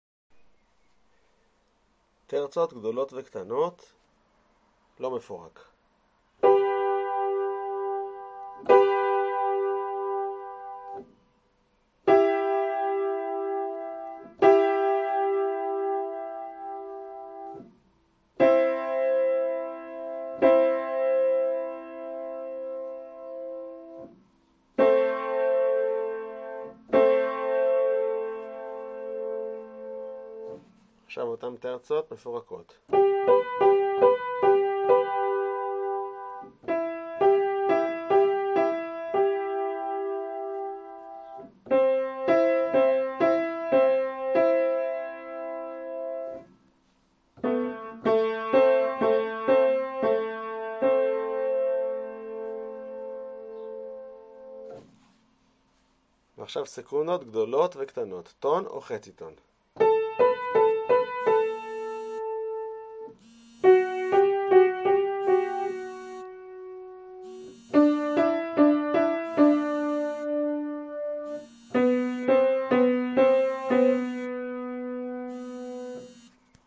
מצרף לכם קובץ שמע נוסף עם תרגילים דומים.
26.2-תרגיל-מרווחים.mp3